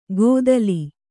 ♪ gōdali